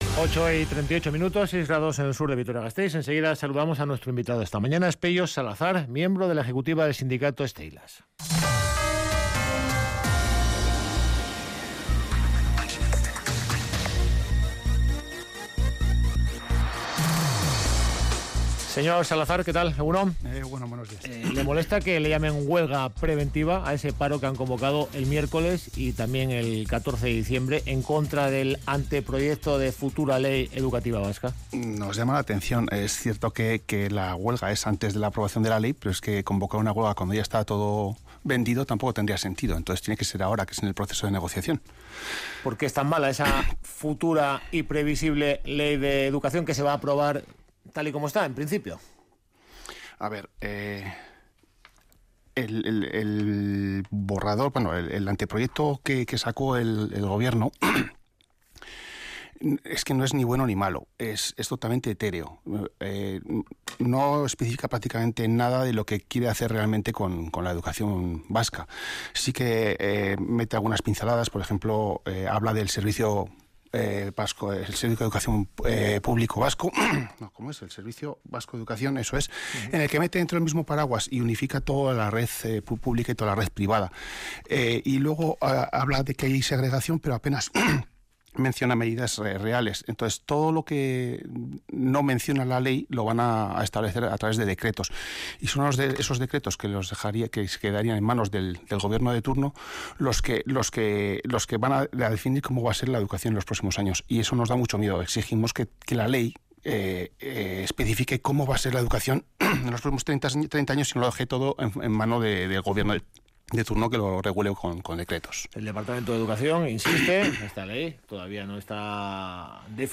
Radio Vitoria ENTREVISTA-DEL-DIA